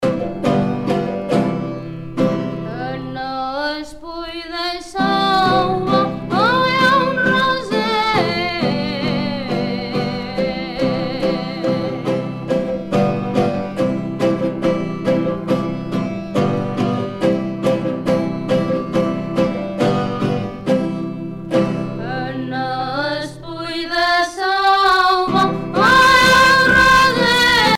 Bolero (Majorque)
danse : boléro
Pièce musicale éditée